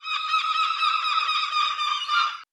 Seagull 003.wav